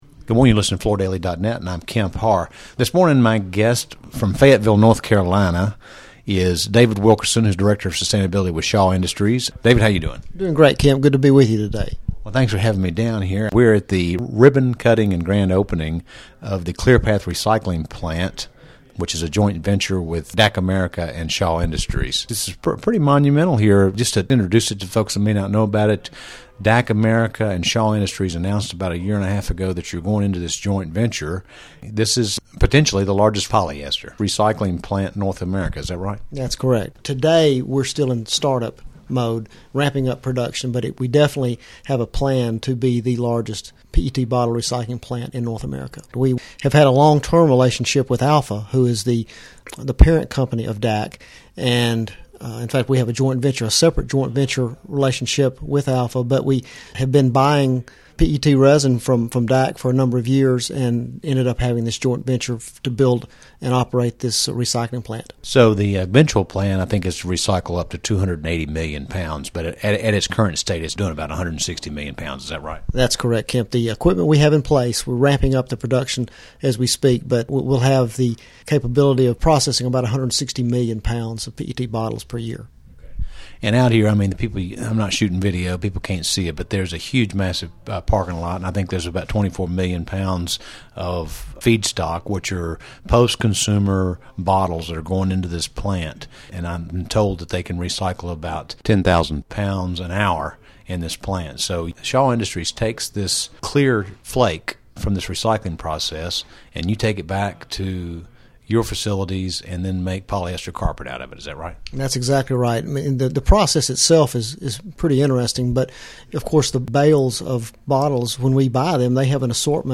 Listen to the interview to hear that this plant is on track to become the largest post-consumer PET bottle recycling plant in North America and also learn more specifics about its capacity and the rising interest of polyester carpet in the residential flooring market.